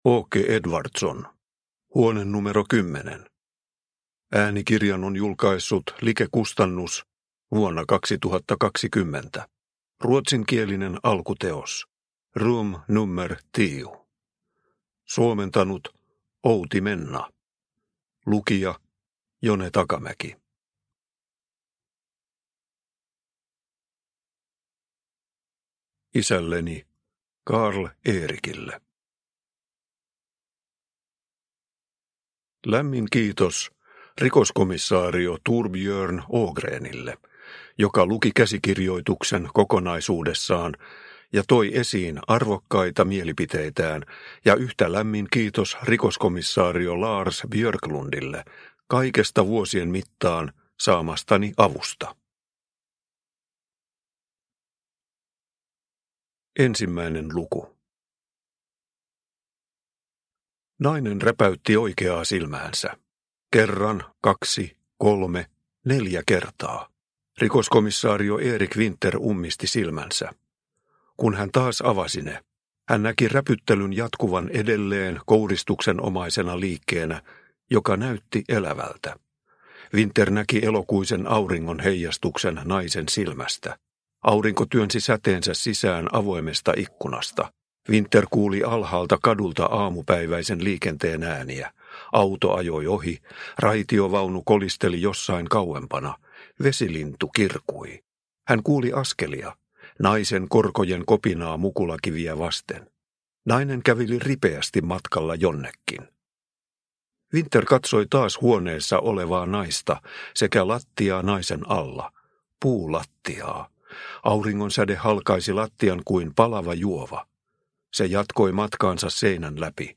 Huone numero 10 – Ljudbok – Laddas ner